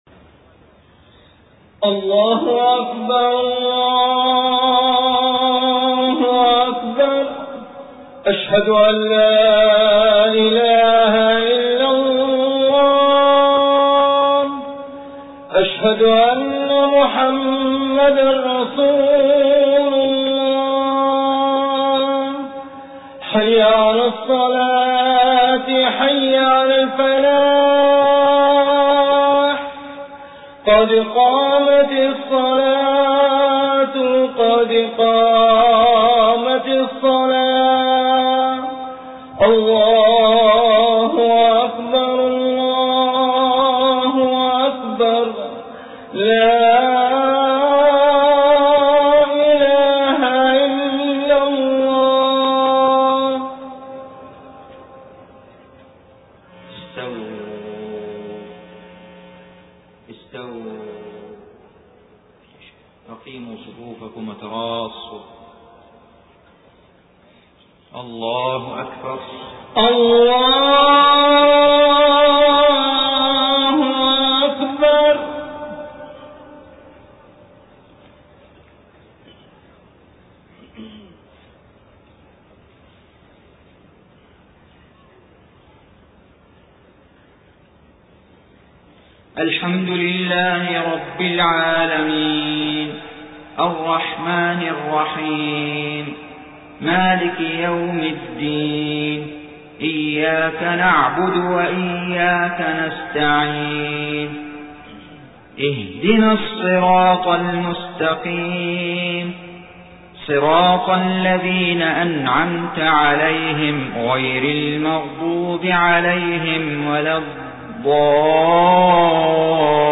صلاة الفجر 24 صفر 1431هـ سورة المعارج كاملة > 1431 🕋 > الفروض - تلاوات الحرمين